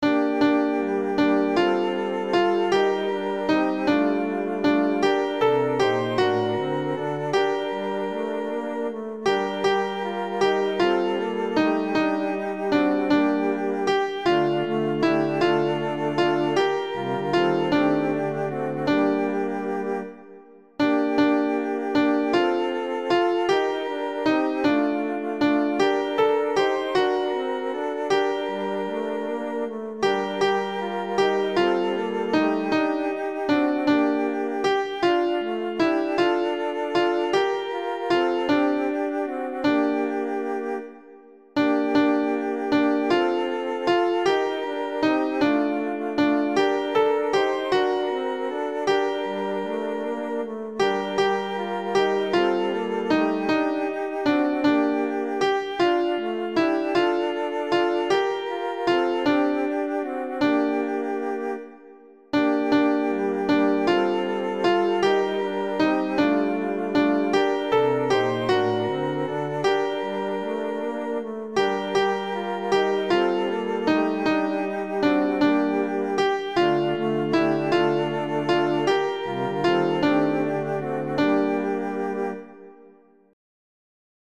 alto
A-Bethleem-Jesus-est-ne-alto.mp3